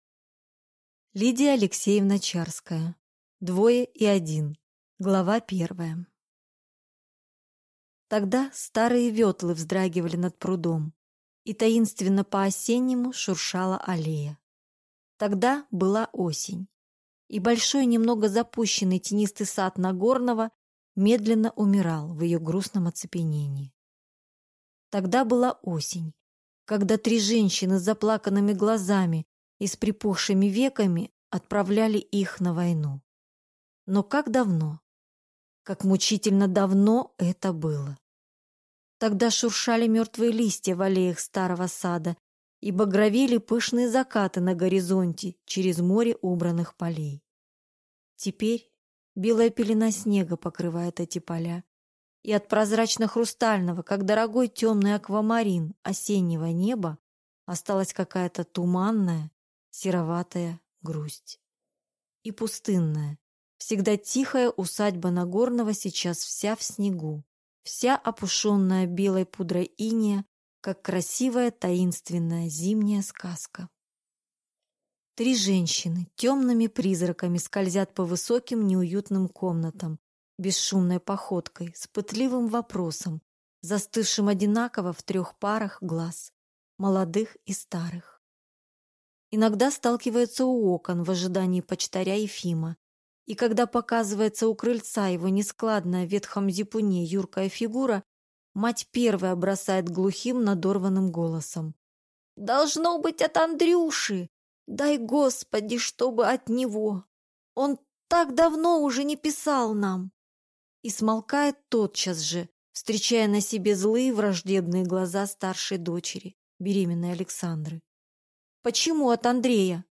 Аудиокнига Двое и один | Библиотека аудиокниг
Прослушать и бесплатно скачать фрагмент аудиокниги